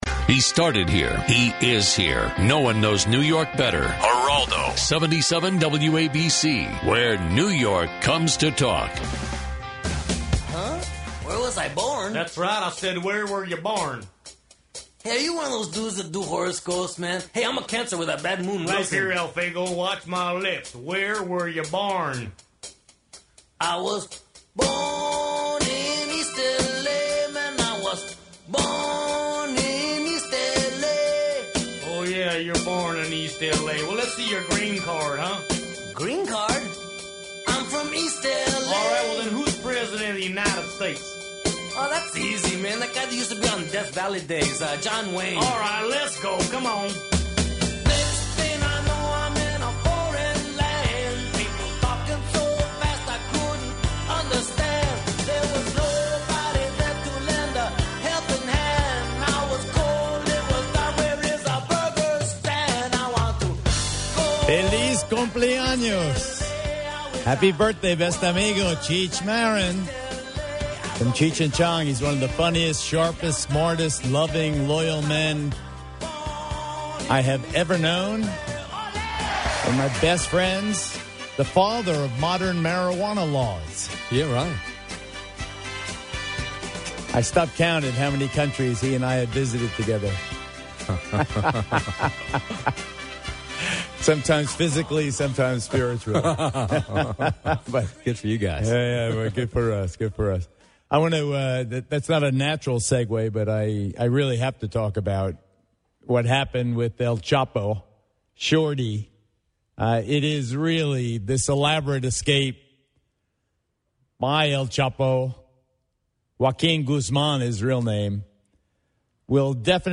Geraldo Rivera covers the latest headlines after the weekend plus has a special visit from the President of Peru!